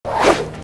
punch3_1.aac